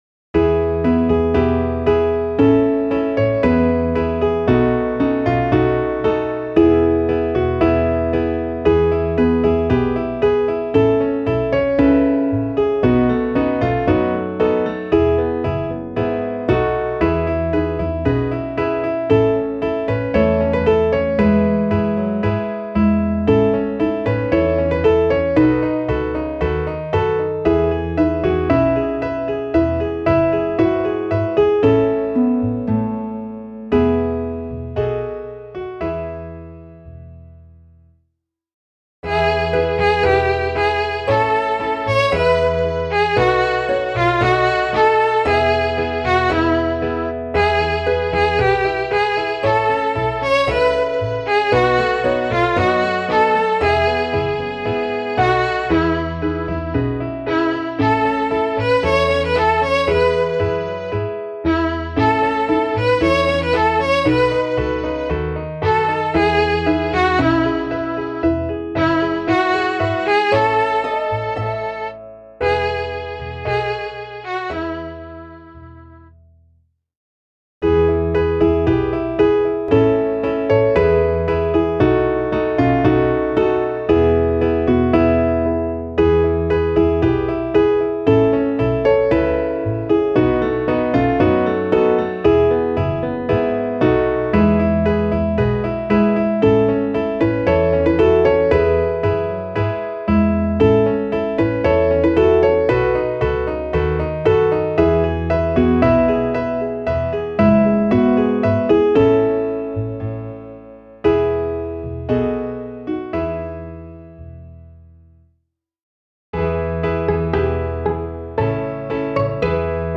Klaviersätze F bis H